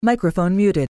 Microphone-Muted-Teamspeak.mp3